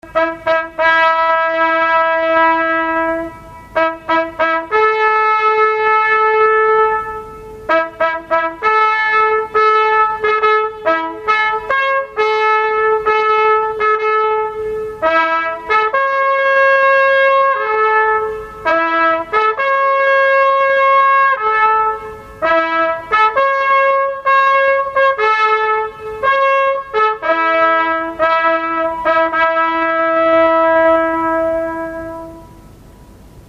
哀愁漂うラッパの音です。